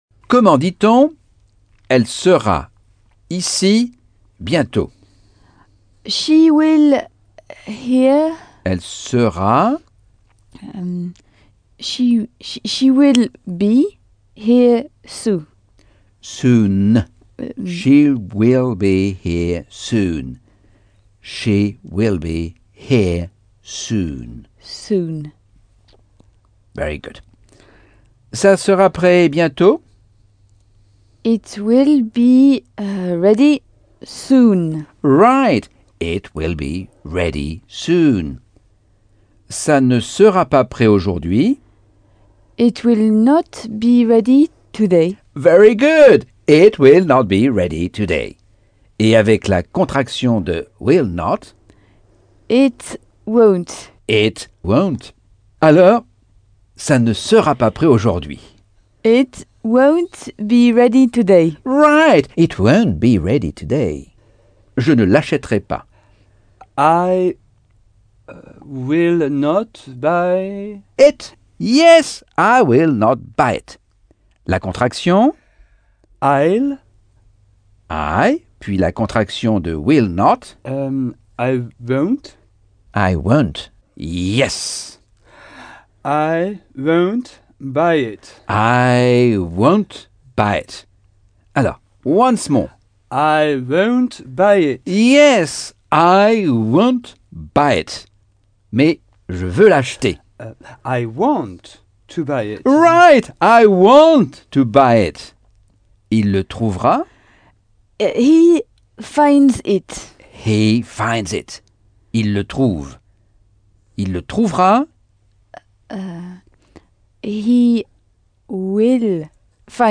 Leçon 3 - Cours audio Anglais par Michel Thomas - Chapitre 5